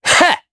Neraxis-Vox_Attack1_jp.wav